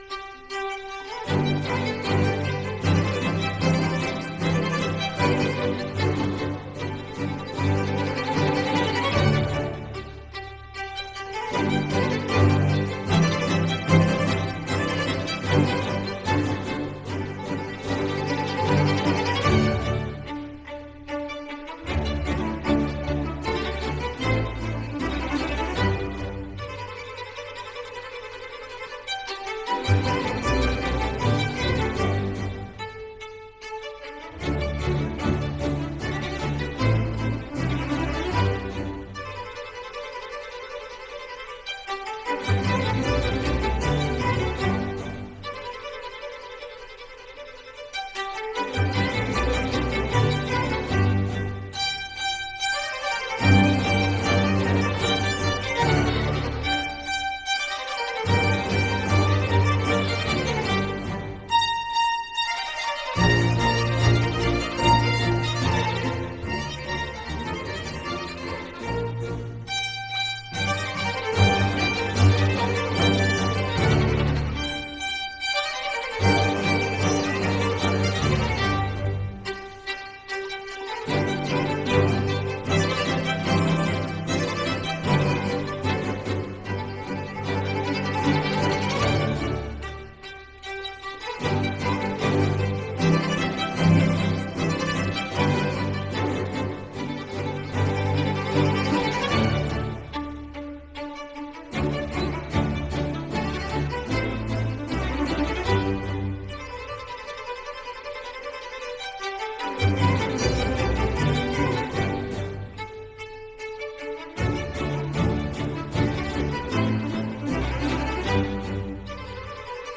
three violins, viola and two cellos
which is a lively dance from Provence